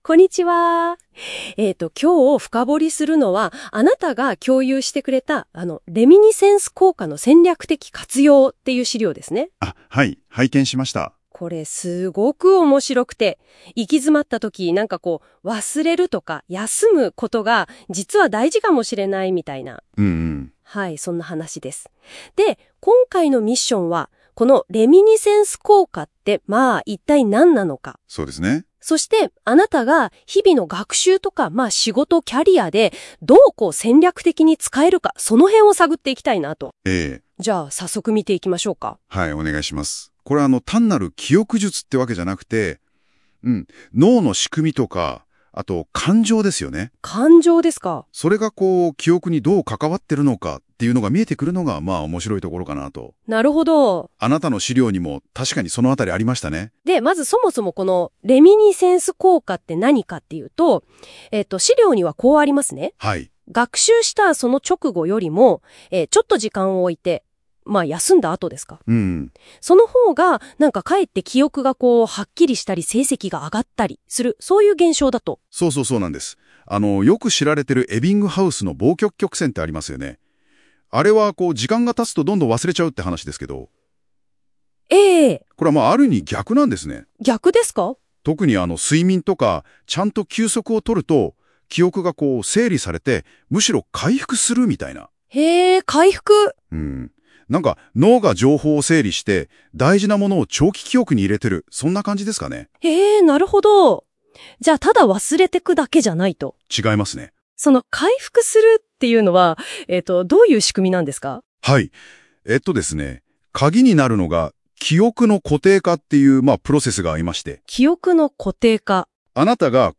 音声解説：行き詰まったら「意図的に忘れる」？レミニセンス効果で記憶・仕事・人生を好転させる戦略的休息術
今日も音声解説を生成しました。
ラジオ感覚で聞けて、そして自分自身に問いかける。